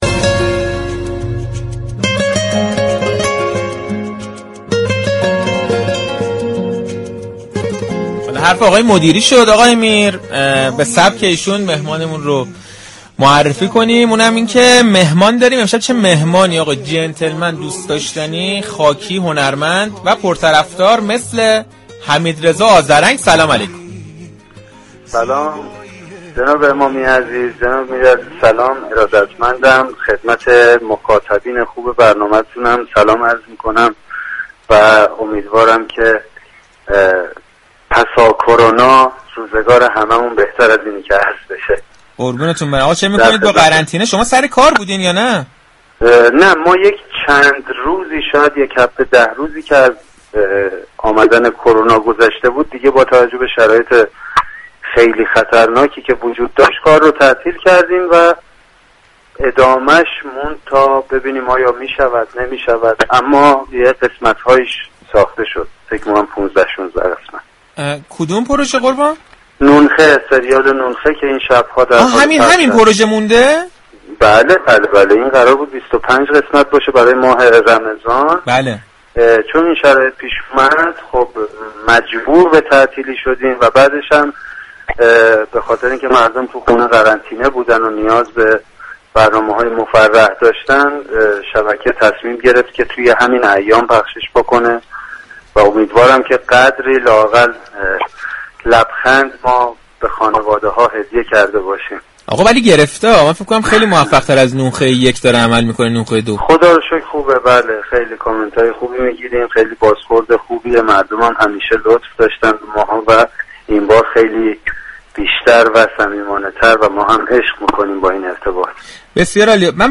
حمیدرضا آذرنگ، نمایشنامه نویس، كارگردان و بازیگر تئاتر و سینما و تلویزیون در گفتگوی تلفنی با برنامه صحنه 31 فروردین رادیو تهران از نیمه كاره ماندن سریال نون خ، مشكلات بحران كرونا و عدم حمایت دولت از مردم گفت.